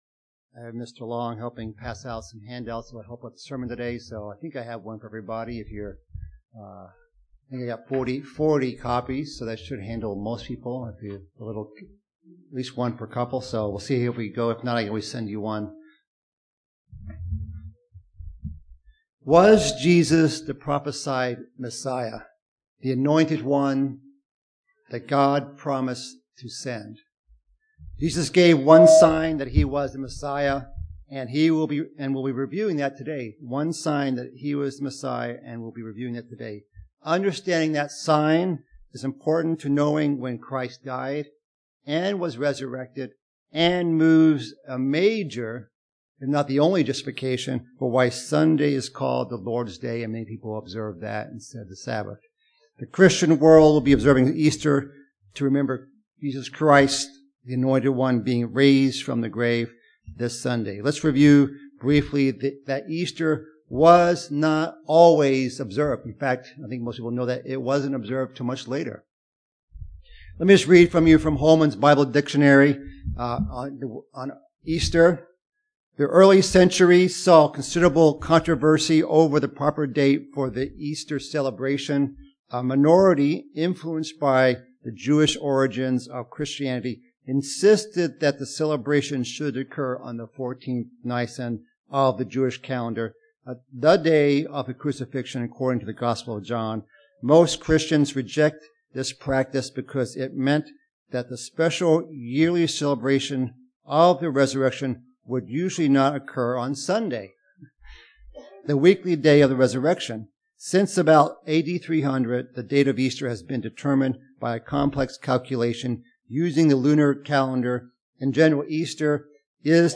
Christ gave the world one sign that He was the Messiah, and yet it is often misunderstood and miscalculated. This sermon explains the details of the 3 days and 3 nights, and why Good Friday to Easter Sunday doesn't add up.